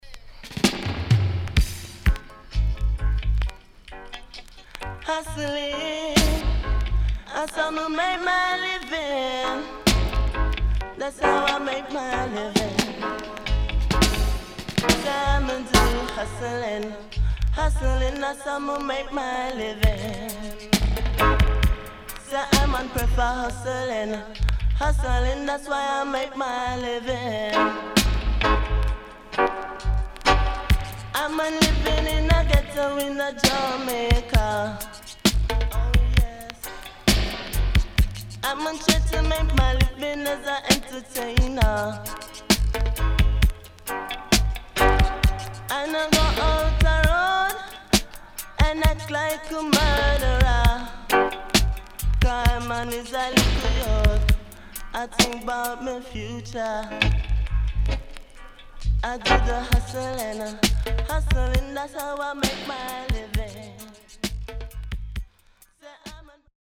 HOME > DISCO45 [VINTAGE]  >  KILLER & DEEP
SIDE A:序盤ノイズ少なめですが、中盤傷によるパチノイズ周期的に入ります。